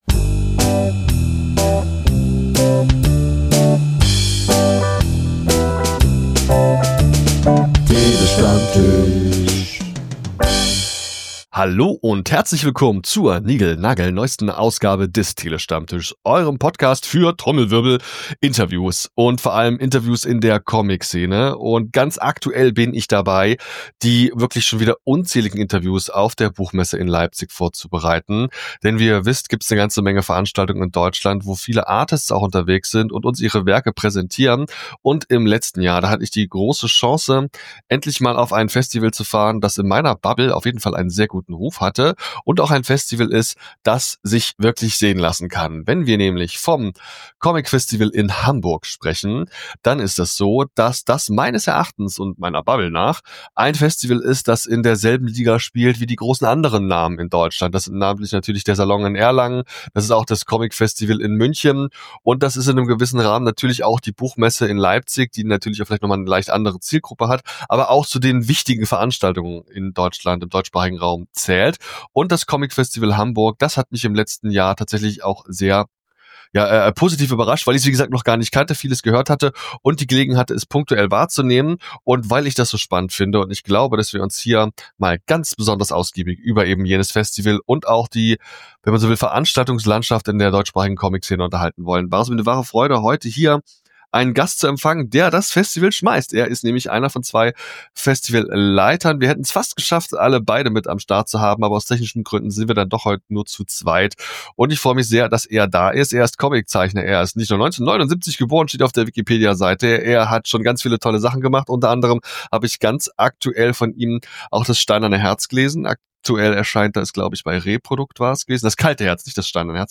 Comictalks & Interviews Podcast